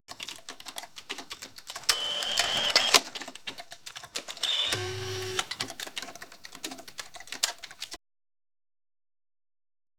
（2）音效叠加：支持多段音效叠加，比如暴雨天的场景，涉及雨声、风声、打雷声等多种类型声音的混合，可以通过“音效叠加”来实现层次分明且动态变化的音效，具备影视级混音表达力。
例如用它生成键盘声咖啡机的复合音效。
提示词：{“提示”: “@{敲打键盘 & <0.00,8.00>}@{打印机噪声 & <2.00,3.00>}@{咖啡机 & <4.50,5.50>}”,”开始秒数”: 0,”开始秒数”: 8.0}